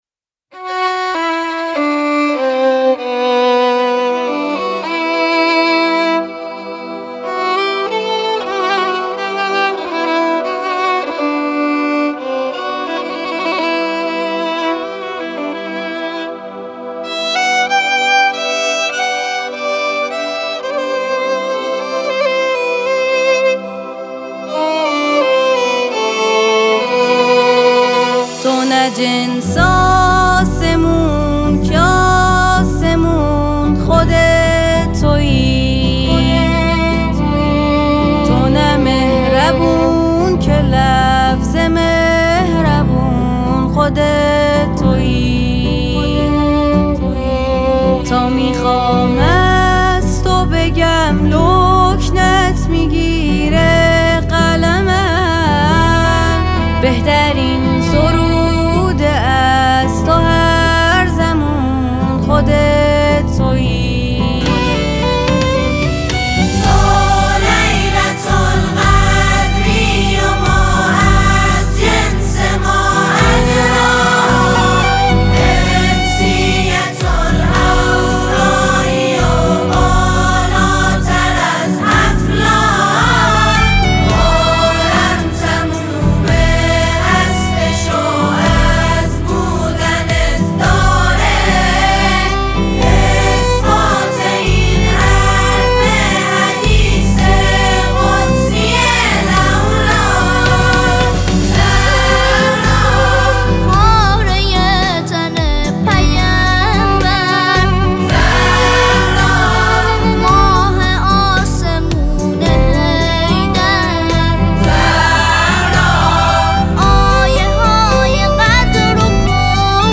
تهیه شده در استودیو نجوا